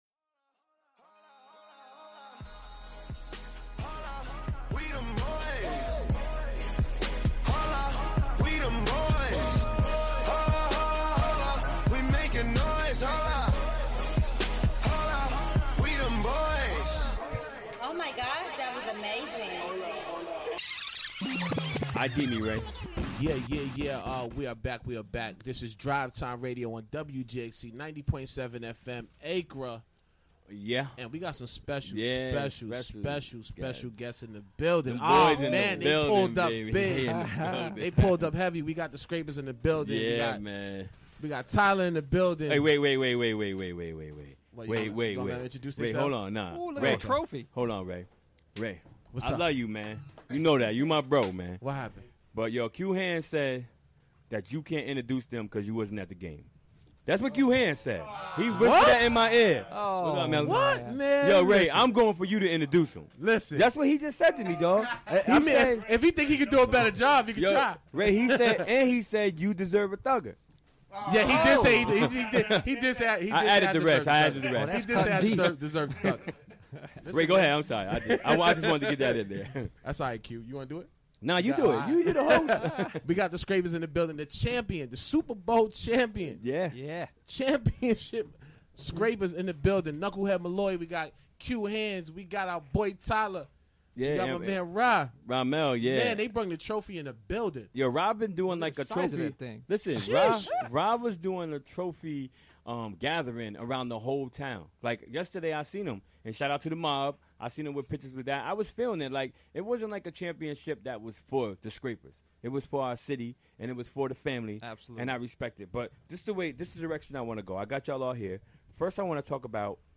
Recorded during the WGXC Afternoon Show on Wednesday, July 13, 2016.